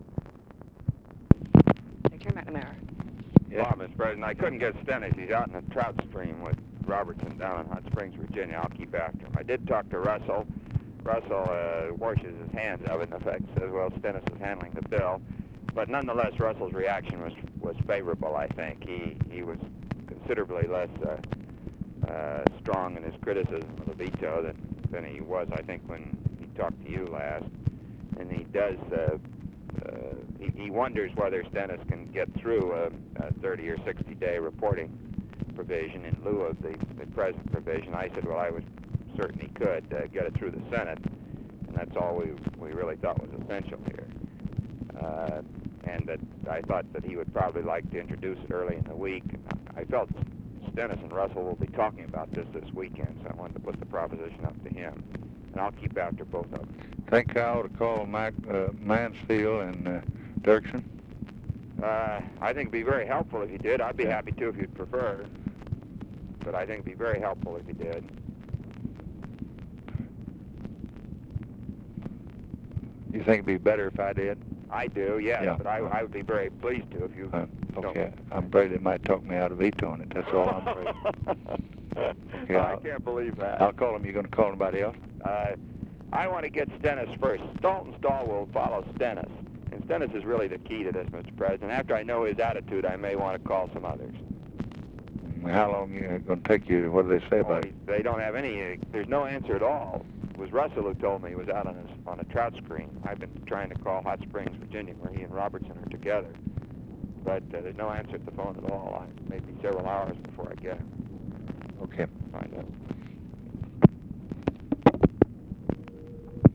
Conversation with ROBERT MCNAMARA, August 21, 1965
Secret White House Tapes